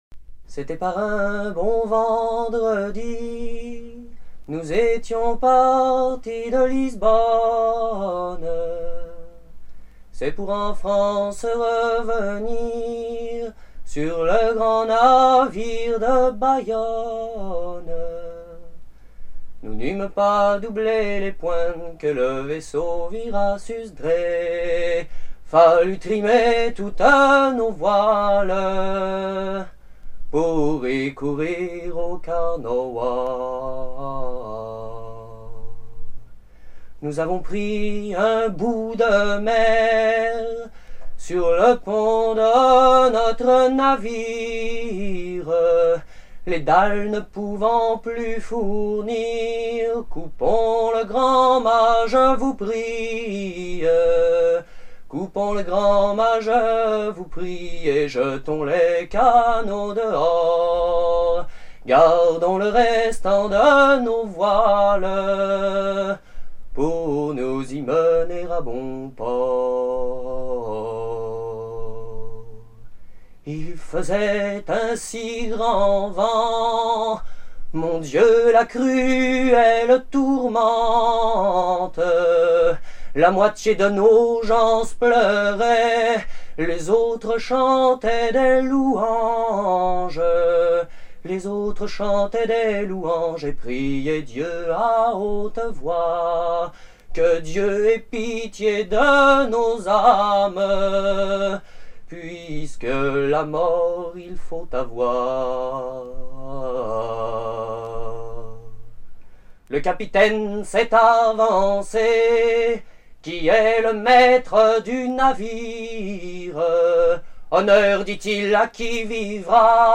Genre strophique
Chants de marins traditionnels